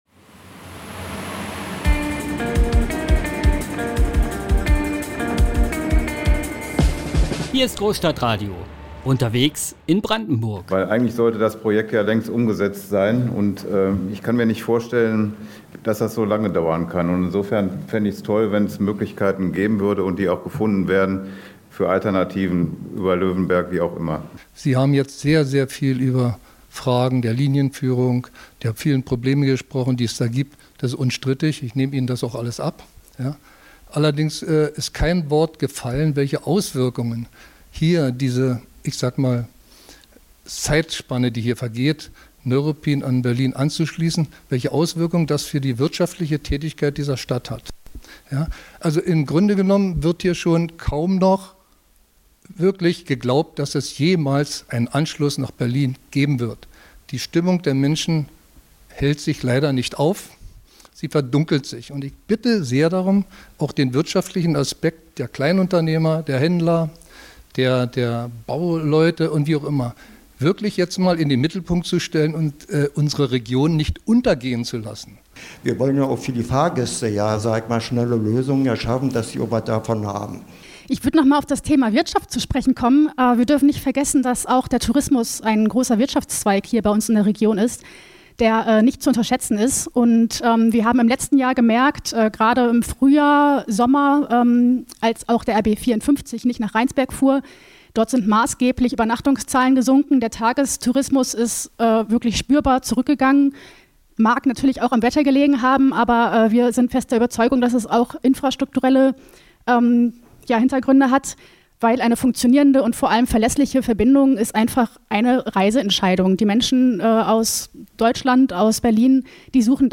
Am Runden Tisch diskutierten nun Experten von Deutscher Bahn, dem Verkehrsverbund Berlin Brandenburg und den Verwaltungen von Kreis und Land über die Frage: Wie und wann bekommt Neuruppin endlich seinen Halbstunden-Takt nach Berlin? Kann ein aktuelles Interimskonzept aus OPR die Lösung sein, dies zeitnah zu ermöglichen?